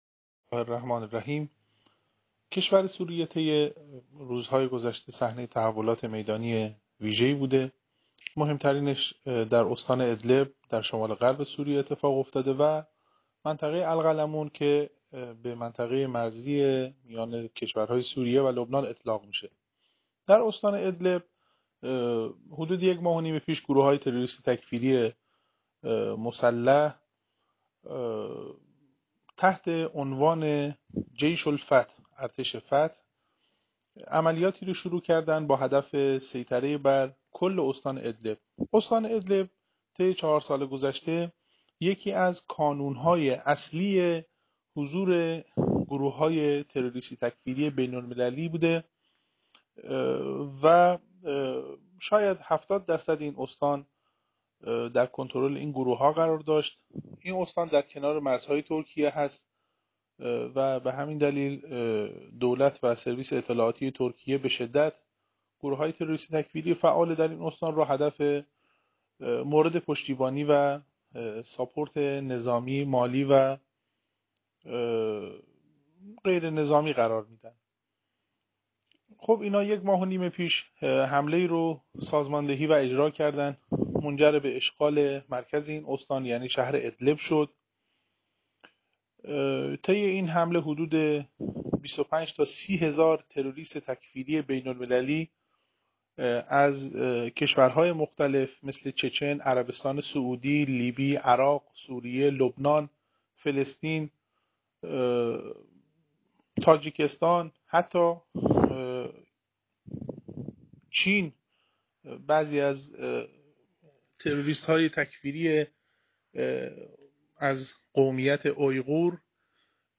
محورهای سخنان کارشناس امروز